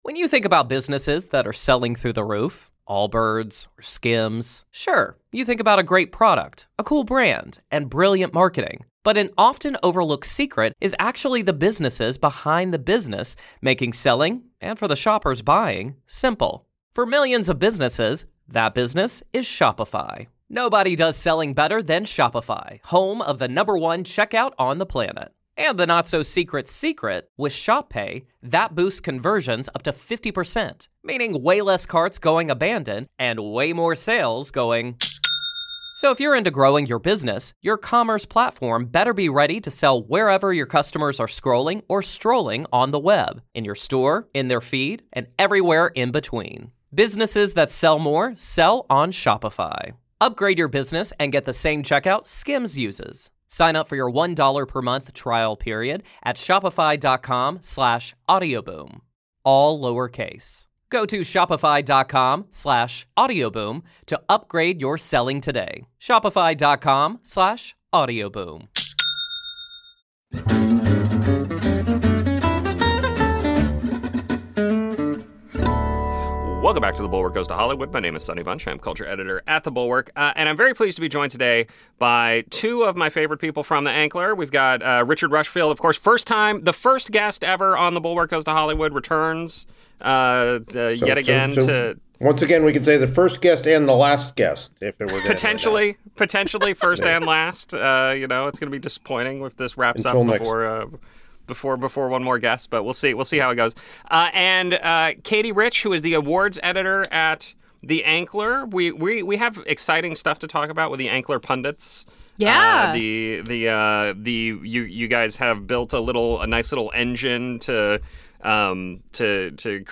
Please excuse any background noise/distortion that you may hear as a result!) We talked about how the Globes might affect the Oscars , how the Globes has devolved into an incestuous web of self-dealing , and how the fires might affect Academy Award voting patterns.